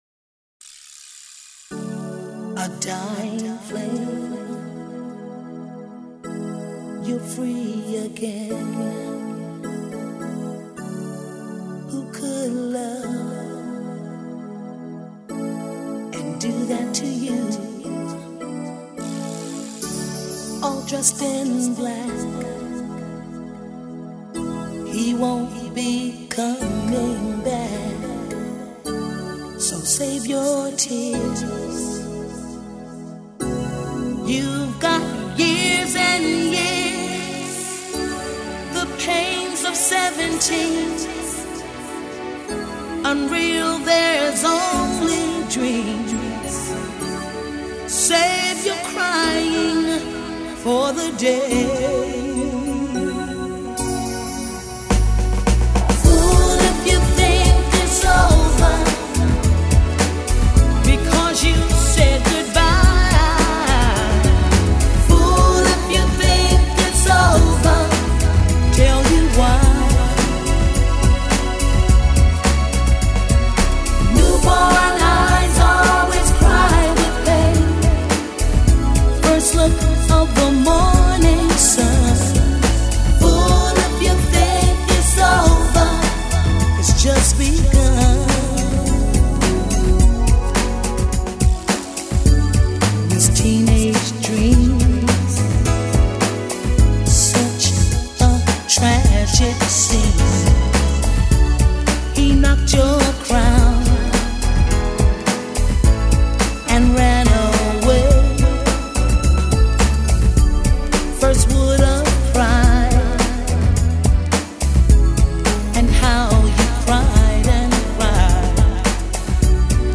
is an American R&B and dance music singer.